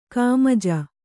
♪ kāmaja